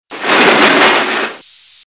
Elevator door
Category: Sound FX   Right: Personal
Tags: Elevator Sounds Elevator Elevator Sound clips Elevator sound Sound effect